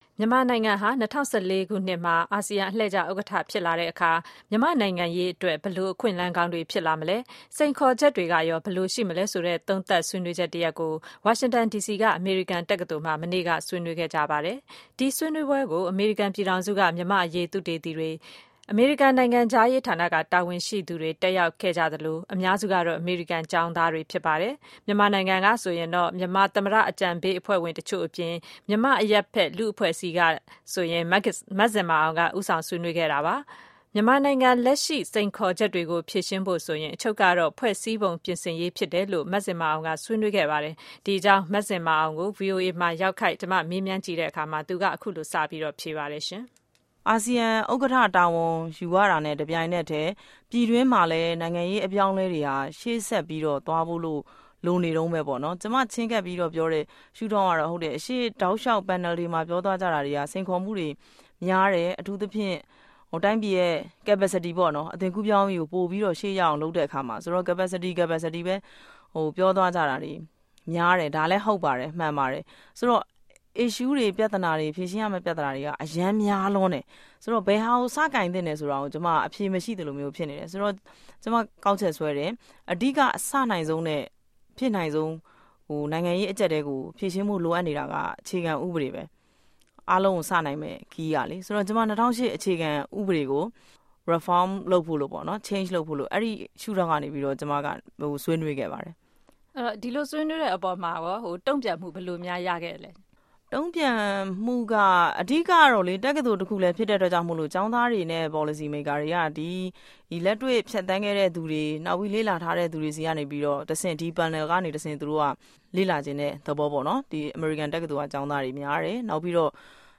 မဇင်မာအောင်နဲ့ ဗွီအိုအေ တွေ့ဆုံမေးမြန်းခန်း